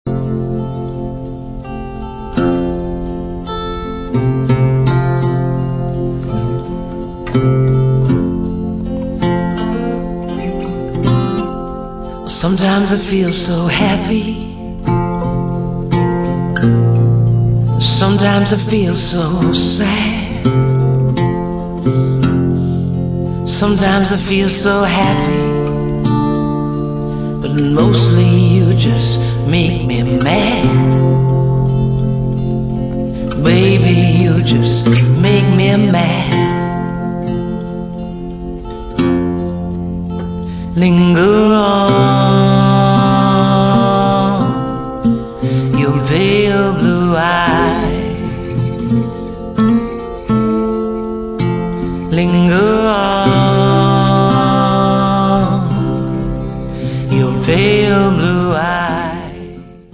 voce
chitarre